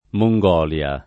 Mongolia [ mo jg0 l L a ]